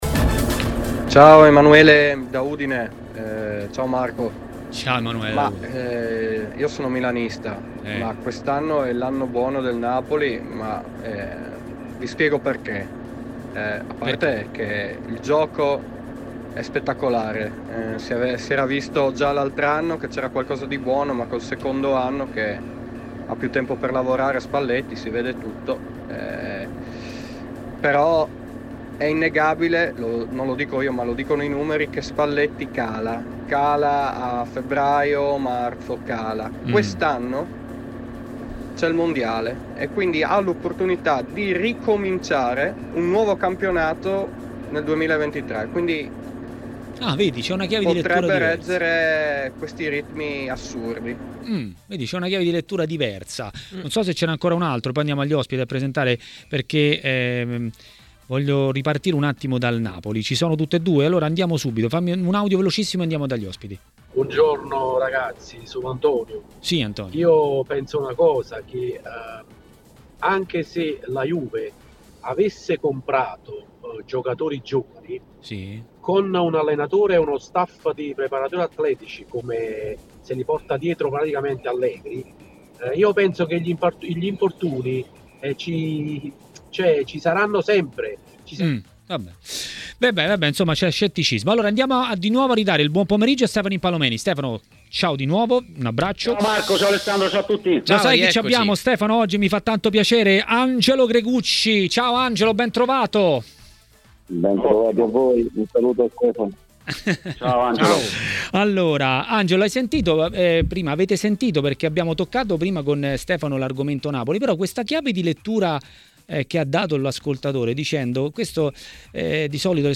Ospite di Maracanà, trasmissione di TMW Radio, è stato l'ex calciatore e tecnico Angelo Gregucci.